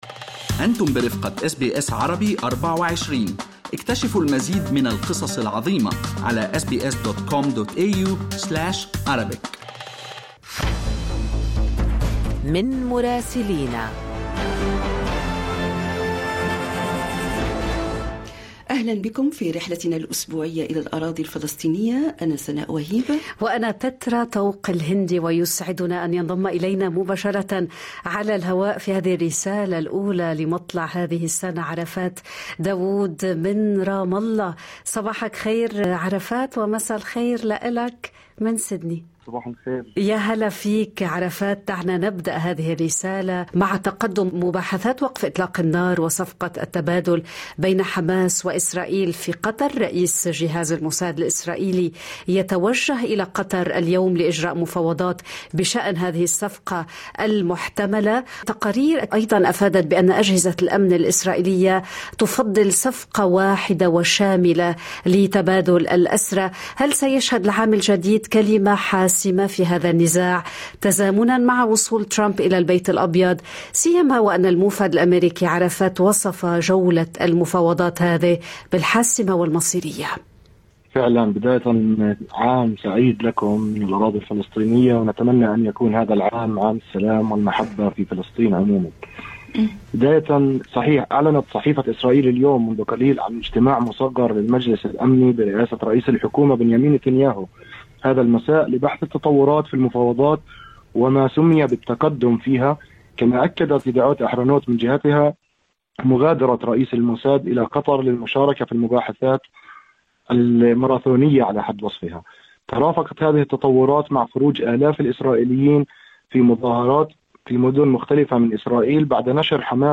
يمكنكم الاستماع إلى التقرير الصوتي من رام الله بالضغط على التسجيل الصوتي أعلاه.